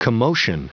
Prononciation du mot commotion en anglais (fichier audio)
Prononciation du mot : commotion